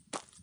Dirt Foot Step 1.wav